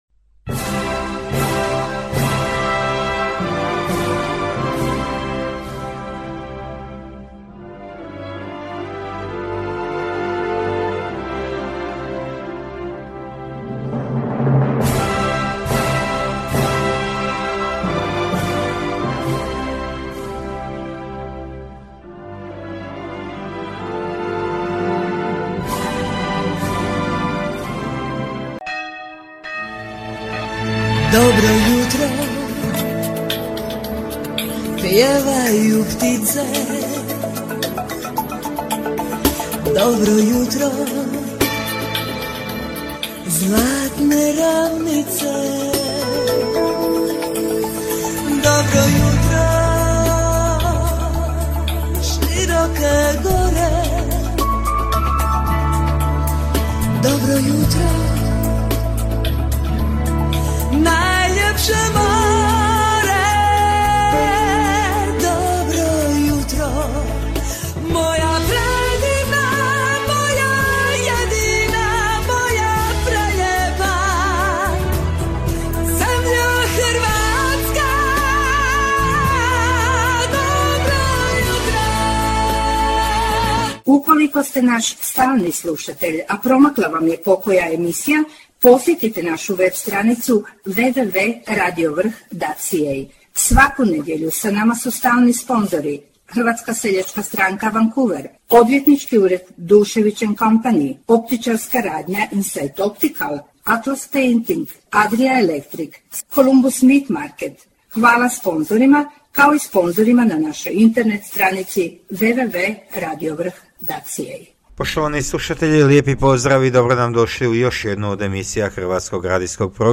Croatian radio, music and news in Vancouver, RadioVRH 1470 AM is proud to present croatian music and news on the air waves.